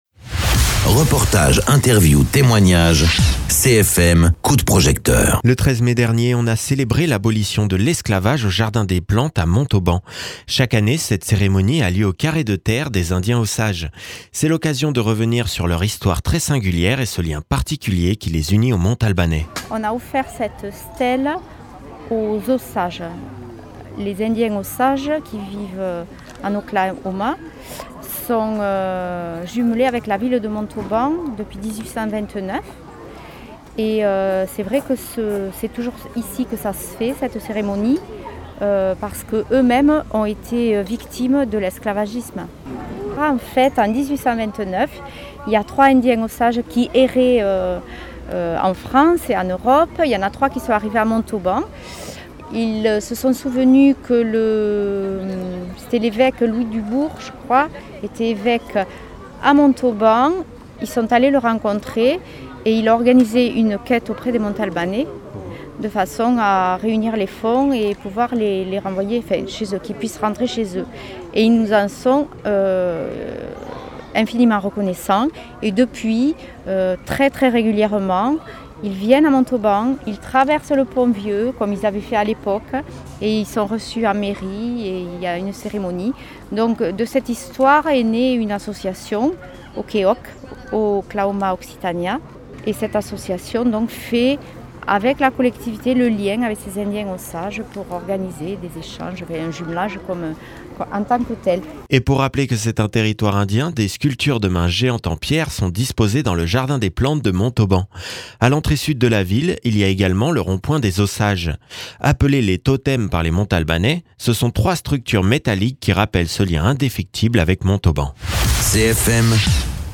Interviews
Invité(s) : Nadine Bon, adjointe déléguée à la coordination de la démocratie participative dans les quartiers.